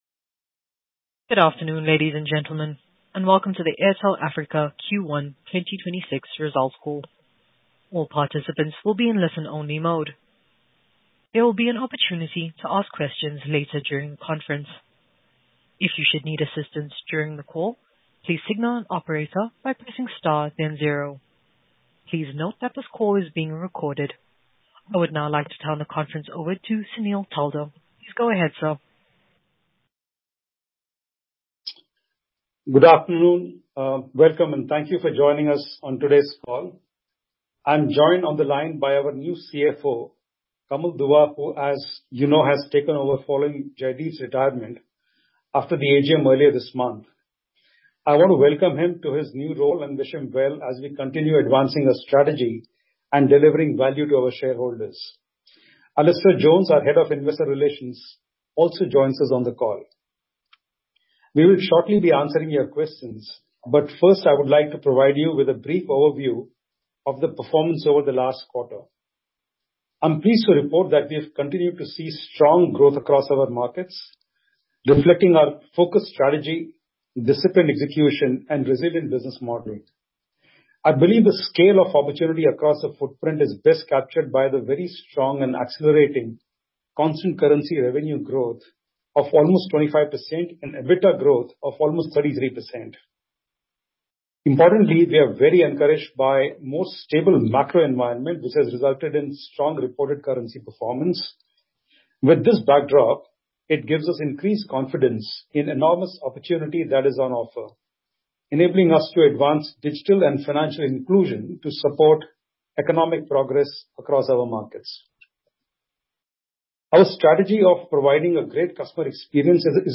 Conference Call Recording Q1 2026